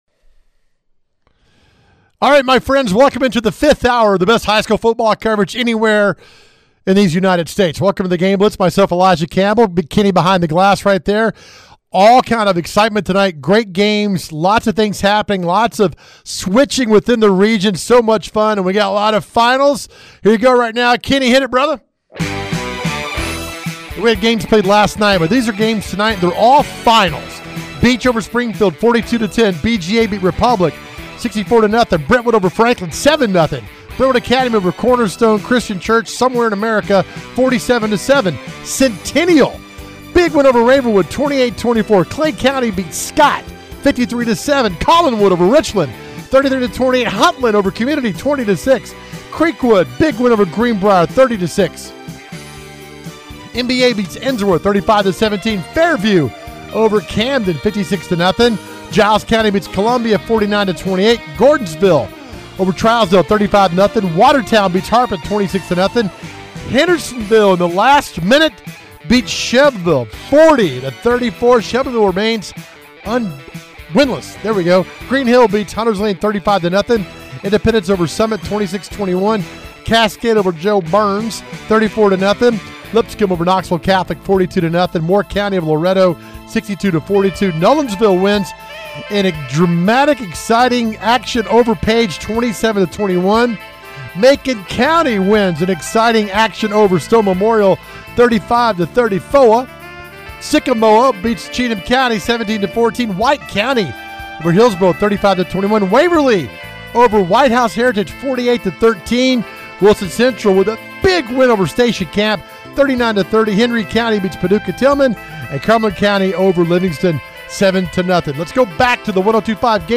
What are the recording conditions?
They bring you six hours of everything in the world of Middle TN High School Football. We have interviews with coaches and reporters live at the hottest games!